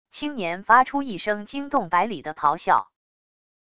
青年发出一声惊动百里的咆哮wav音频